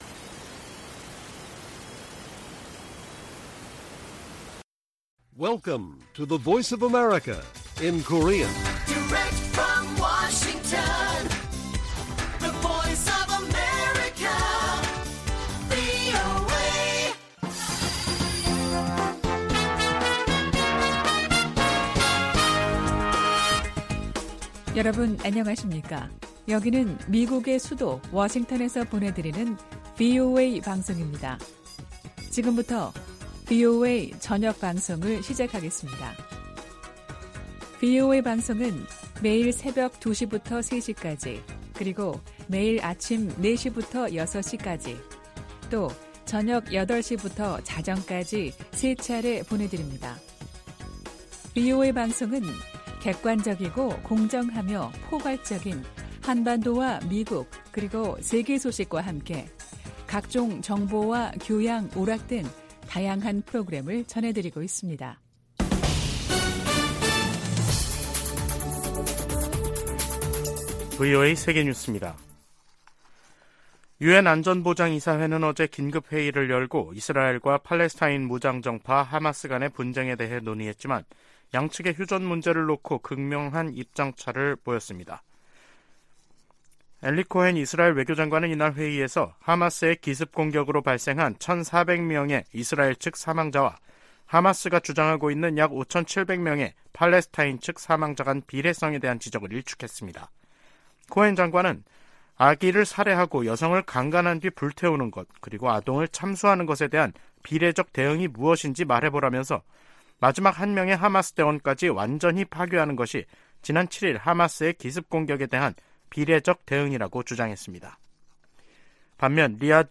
VOA 한국어 간판 뉴스 프로그램 '뉴스 투데이', 2023년 10월 25일 1부 방송입니다. 유럽연합(EU)이 탈북민 강제북송 문제가 포함된 북한인권 결의안을 유엔총회에 제출할 계획입니다. 한국은 유엔총회에서 북한과 러시아에 불법 무기거래를 즉각 중단라고 촉구했습니다.